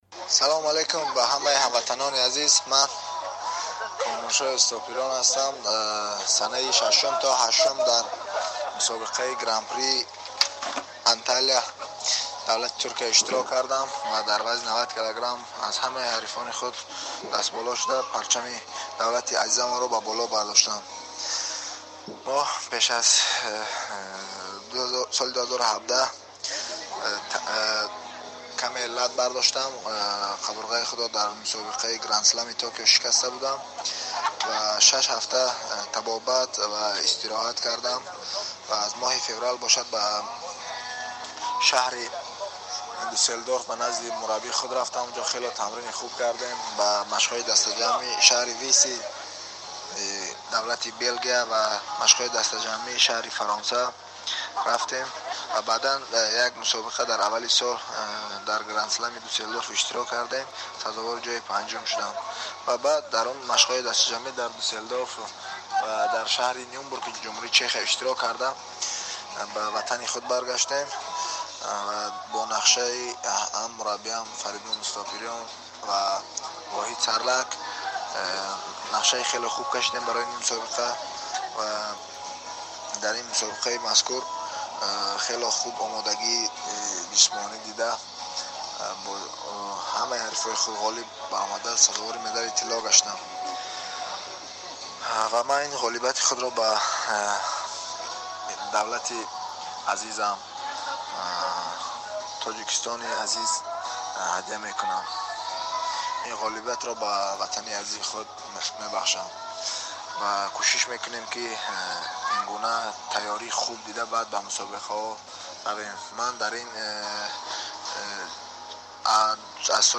Гуфтугӯ бо Комроншоҳи Устопириён, паҳлавони номдори тоҷик.